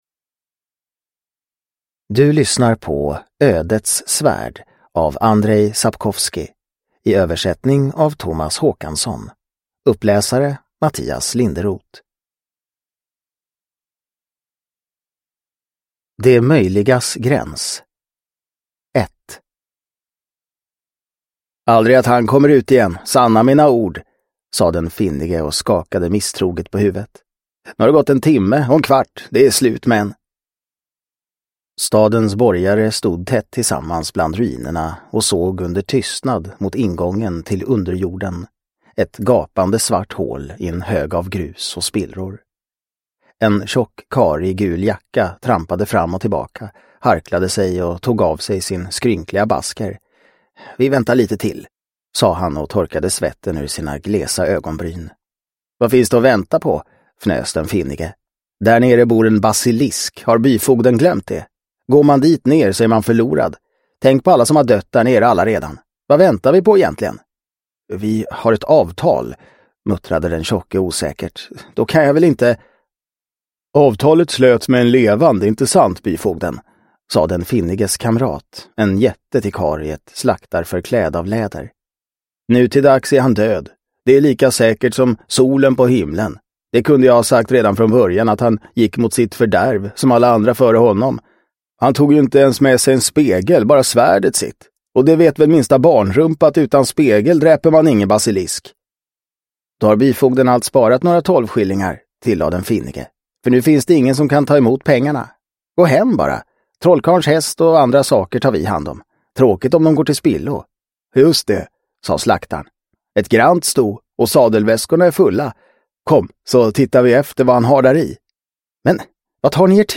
Ödets svärd : berättelser om Geralt av Rivia – Ljudbok – Laddas ner